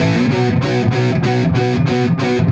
Index of /musicradar/80s-heat-samples/95bpm
AM_HeroGuitar_95-B01.wav